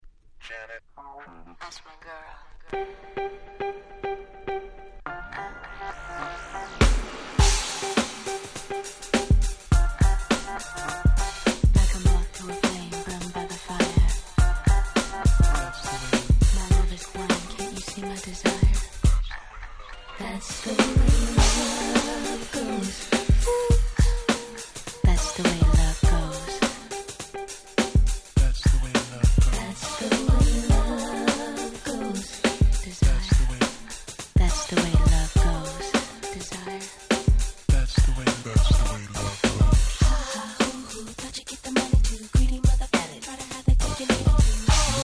ただのMegamixでは無くBeatをしっかり引き直していたりと作りも丁寧。